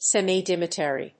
アクセント・音節sèmi・diámeter